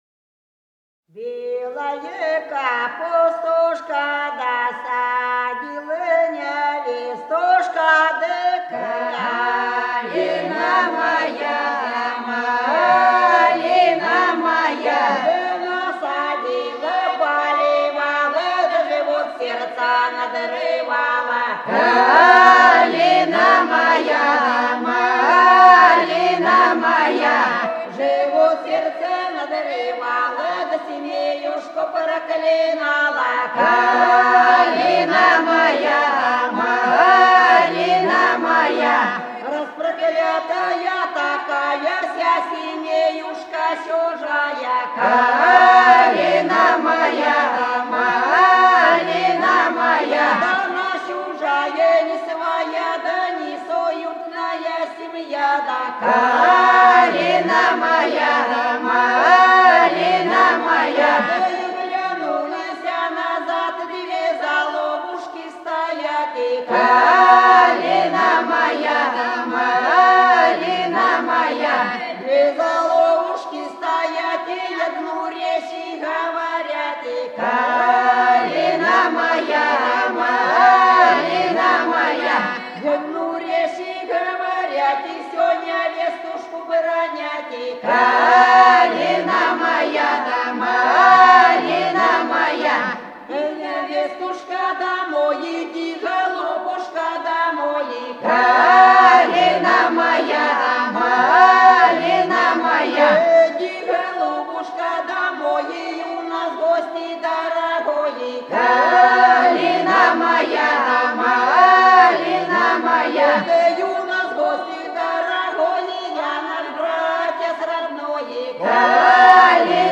Голоса уходящего века (село Фощеватово) Белая капустушка (скоморошная, идут к сватам)
14._Белая_капустушка_(скоморошная,_идут_к_сватам).mp3